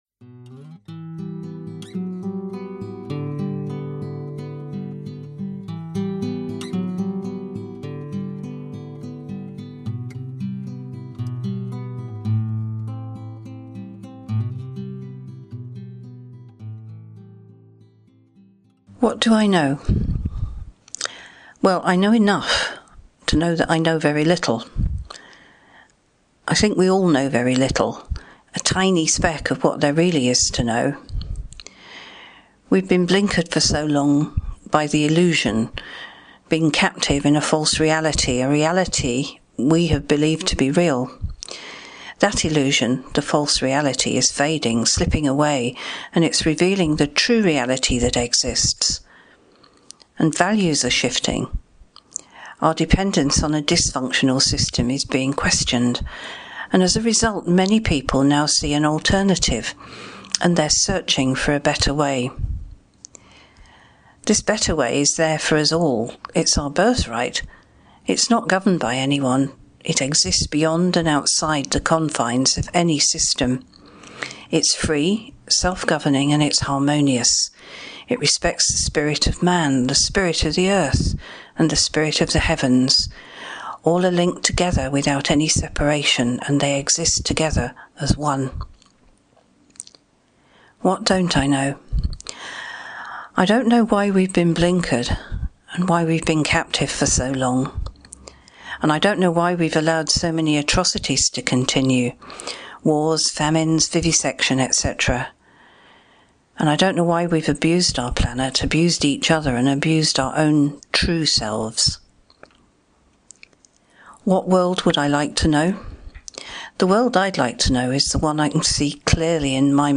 voiceover